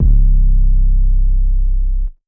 808 (20 Min).wav